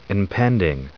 1938_impending.ogg